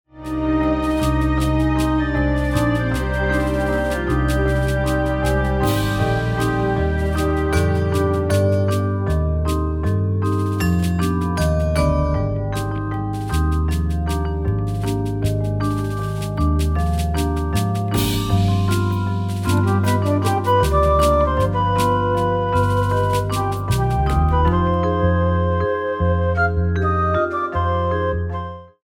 keyboard wind instruments